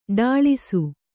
♪ ḍāḷisu